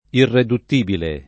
DOP: Dizionario di Ortografia e Pronunzia della lingua italiana